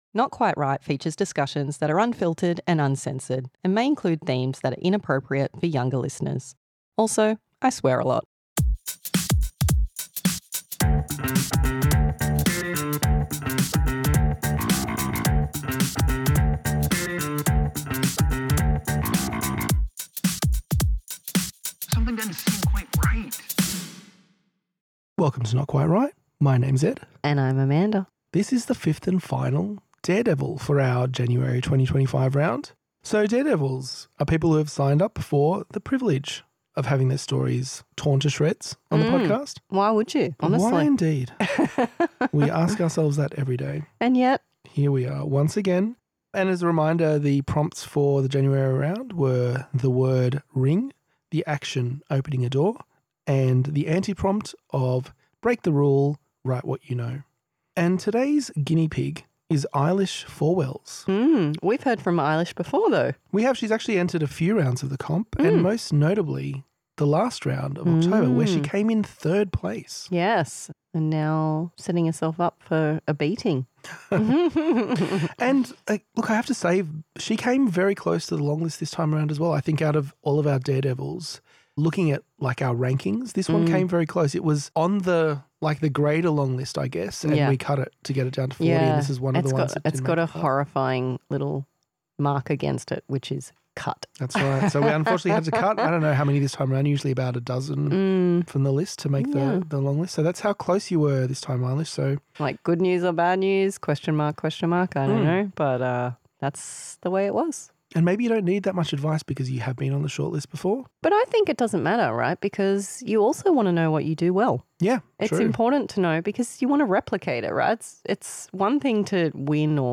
Daredevil Critique